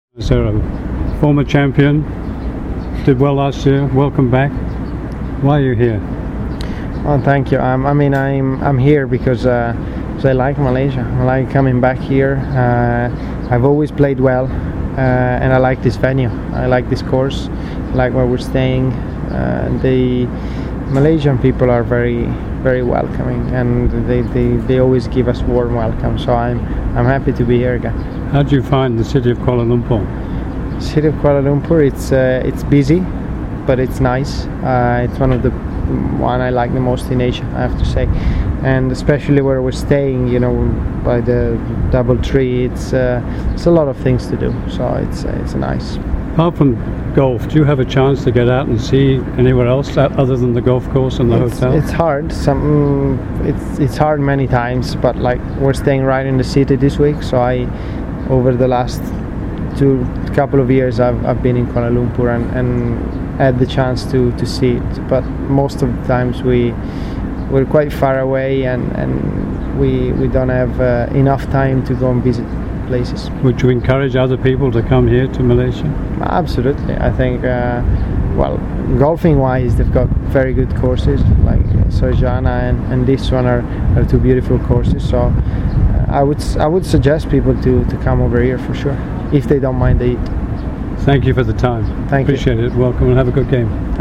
MGTA interviews Matteo Manassero